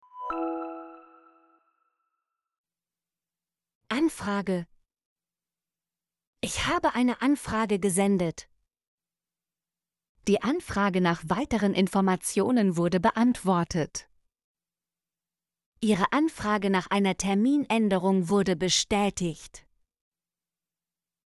anfrage - Example Sentences & Pronunciation, German Frequency List